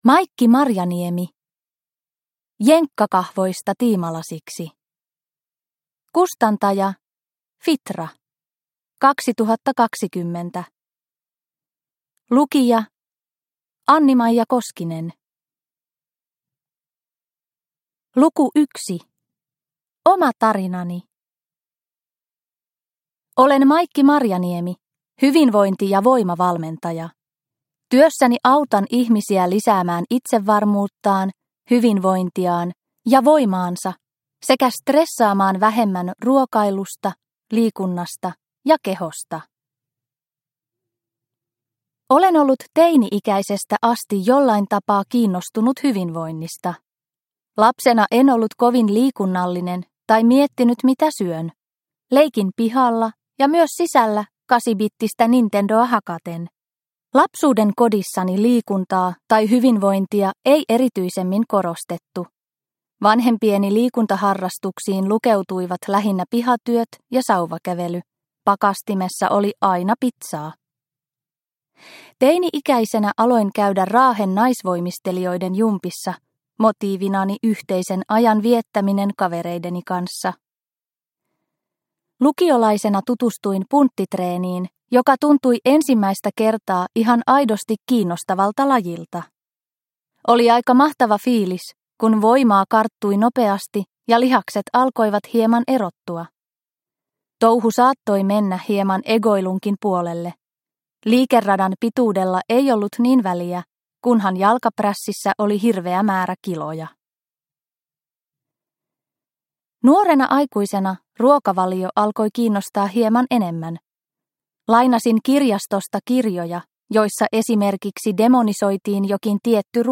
Jenkkakahvoista tiimalasiksi – Ljudbok – Laddas ner